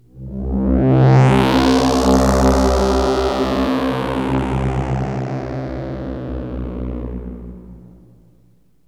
AMBIENT ATMOSPHERES-1 0002.wav